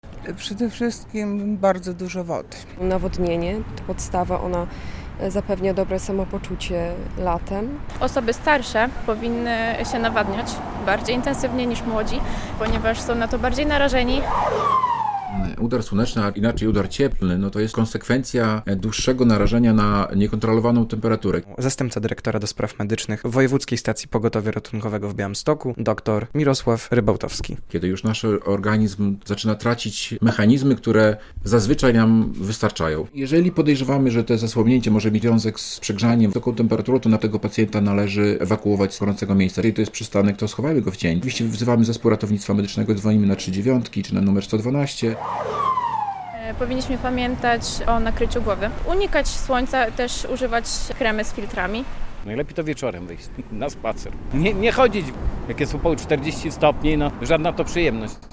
Jak się chronić przed upałem - relacja
Zapytaliśmy Białostoczan, o czym powinniśmy najbardziej w takie dni pamiętać.
Nawodnienie, to podstawa, ono zapewnia dobre samopoczucie latem - mówi mieszkanka Białegostoku.